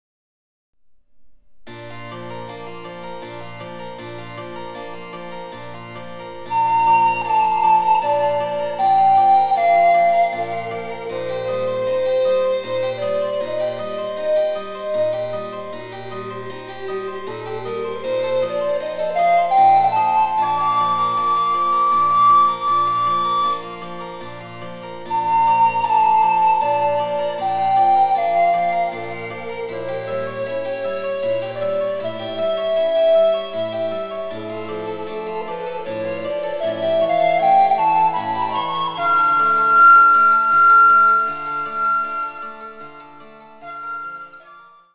★魅惑的なロマン派の小品３曲をアルトリコーダーで演奏できる「チェンバロ伴奏ＣＤブック」です。